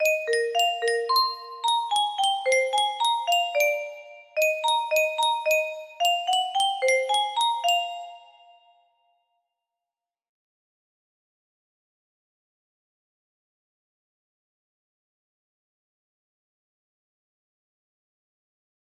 Silly song concept music box melody